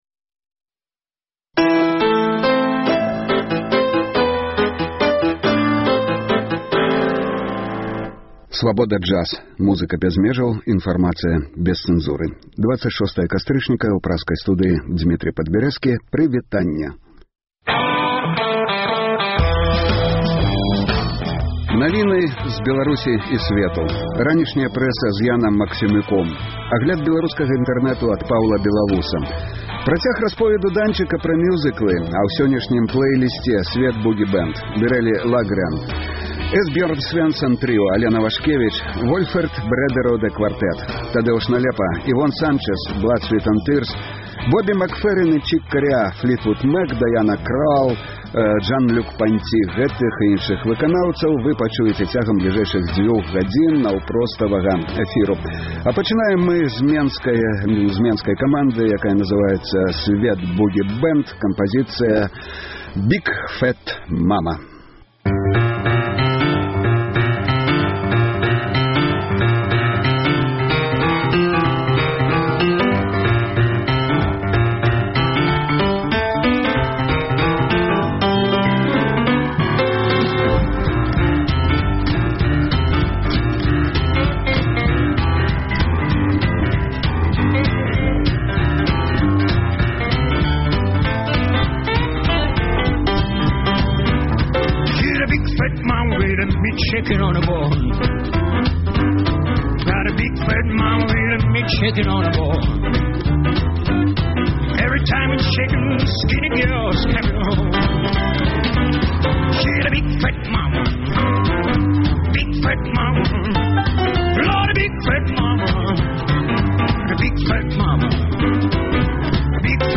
Інтэрнэт-радыё Svaboda Jazz.